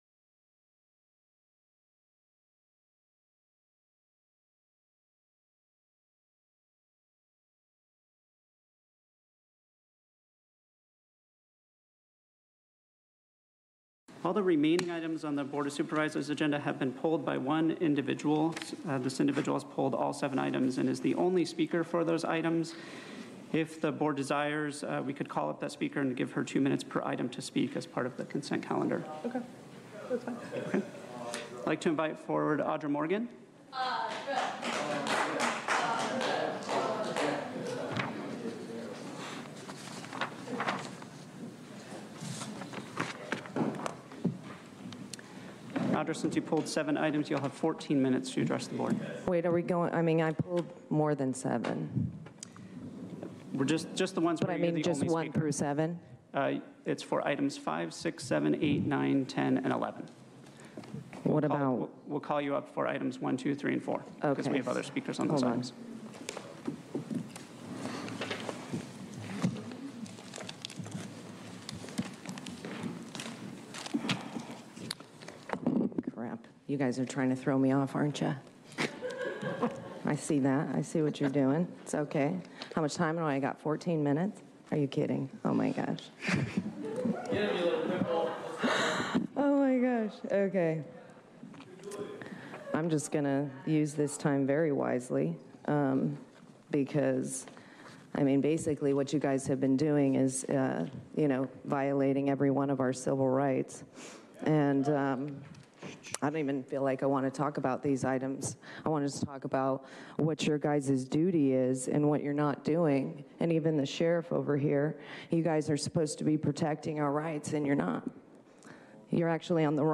San Diego Board of Supervisors!